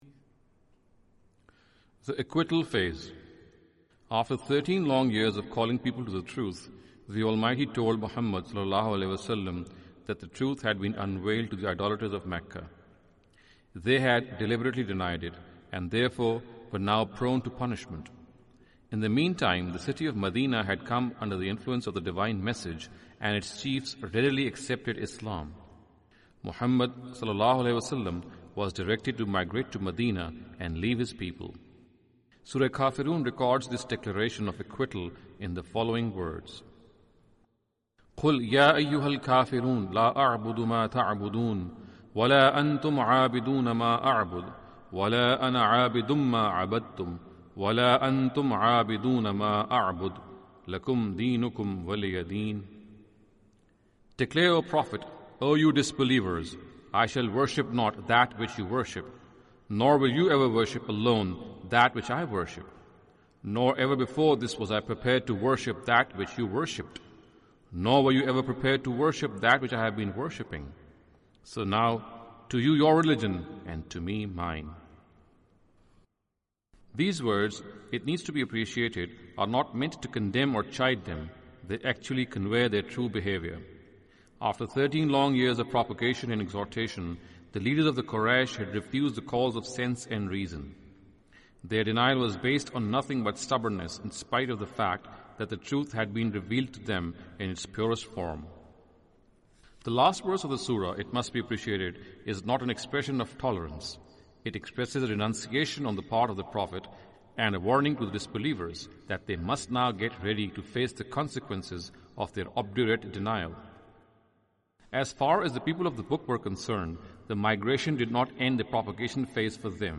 Audio book of English translation of Javed Ahmad Ghamidi's book "Playing God".